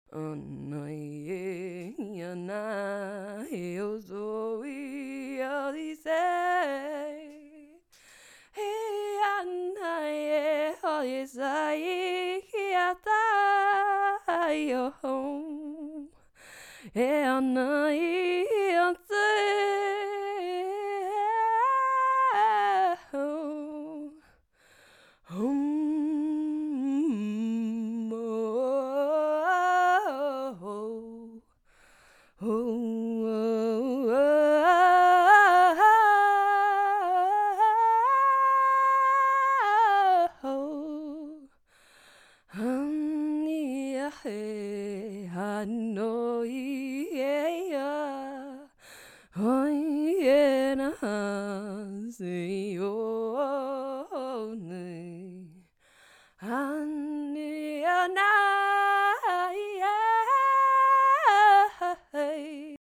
A Healing Voice Transmission to Soften, Expand, and Receive